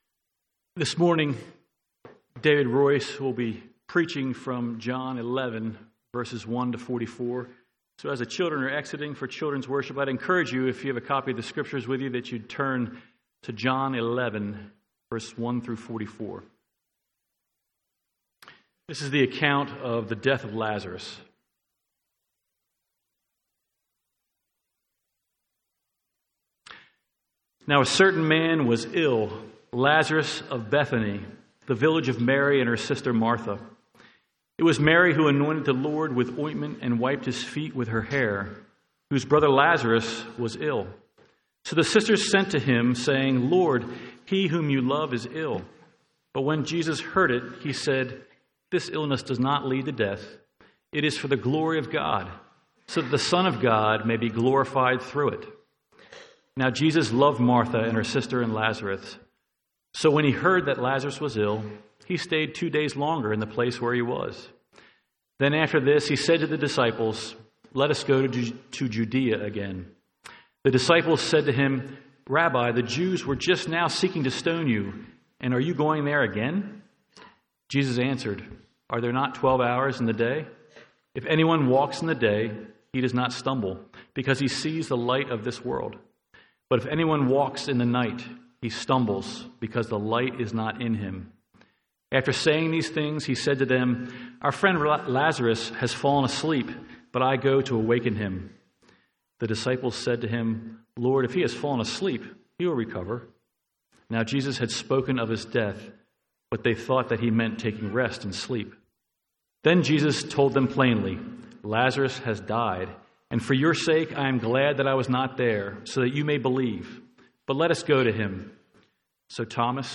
Sermons on John 11 — Audio Sermons — Brick Lane Community Church